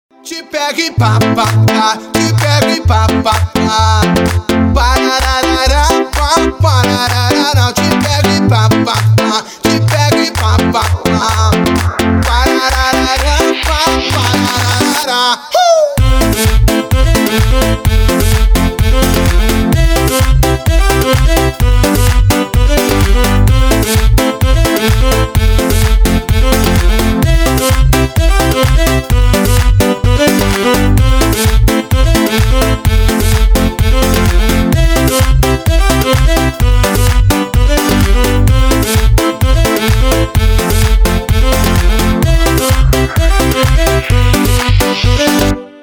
• Качество: 320, Stereo
мужской вокал
громкие
веселые
заводные
Electronic
EDM
электронная музыка
клавишные
Dance Pop